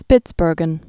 (spĭtsbûrgən)